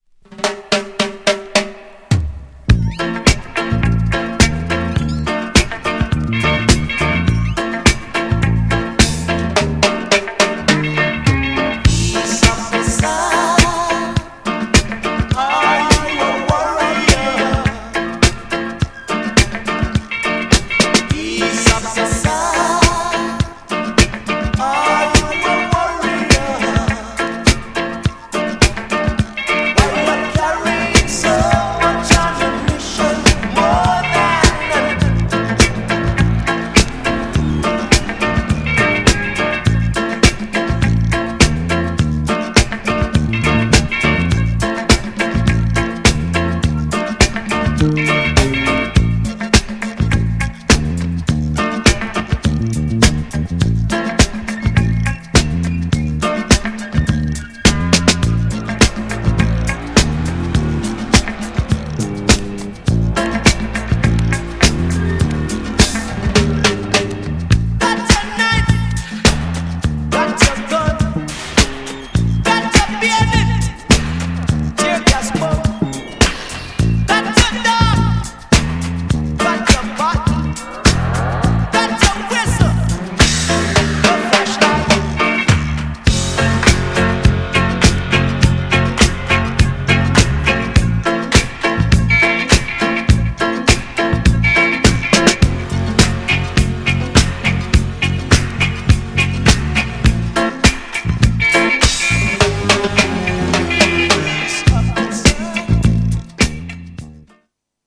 ハッピーなメロディにド度派手なエフェクトが効きまくったエレクトロDUB!!
GENRE Dance Classic
BPM 81〜85BPM
エフェクトがすごい # エレクトロ # スモーキー # ダビー # レゲエ